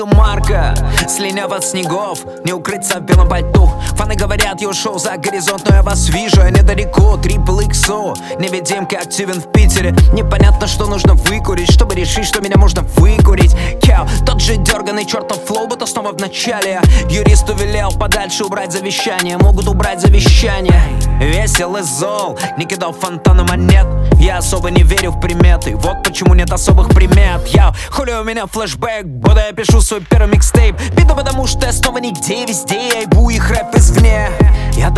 Rap Hip-Hop Rap